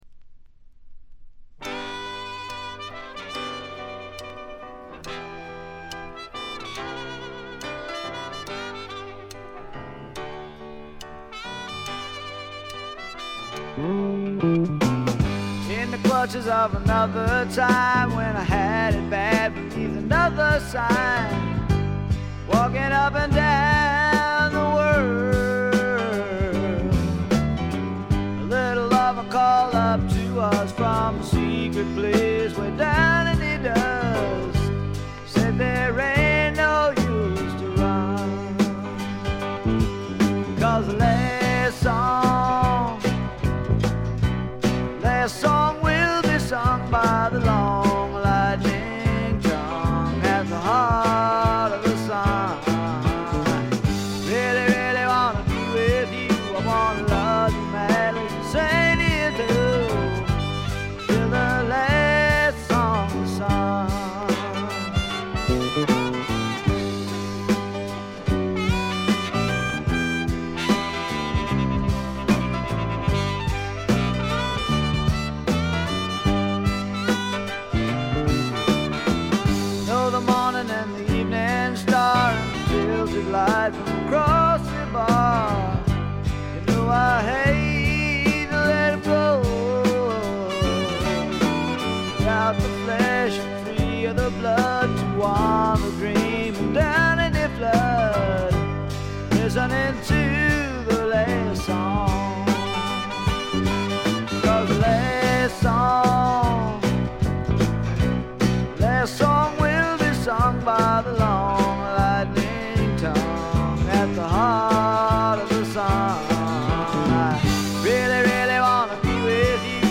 軽微なバックグラウンドノイズ、チリプチ程度。
聴くものの心をわしづかみにするような渋みのある深いヴォーカルは一度聴いたら忘れられません。
試聴曲は現品からの取り込み音源です。